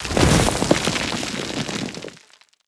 sounds / weapons / _boom / mono / dirt1.ogg
dirt1.ogg